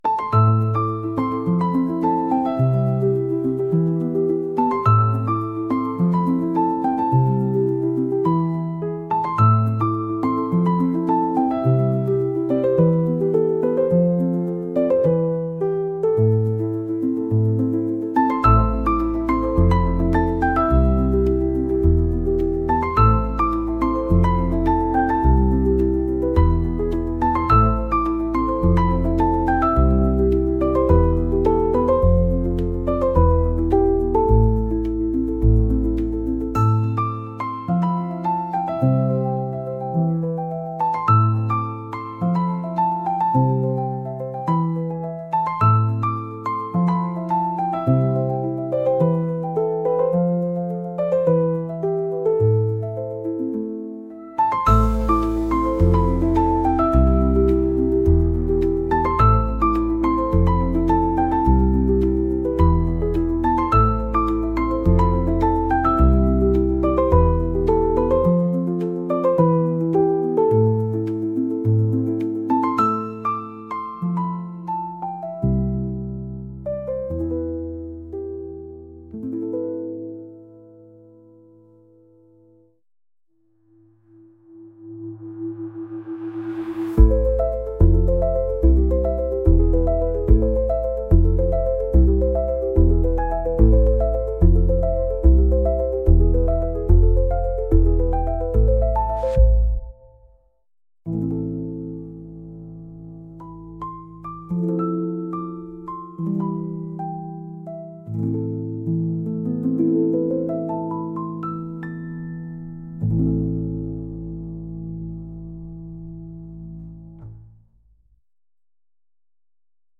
大人な雰囲気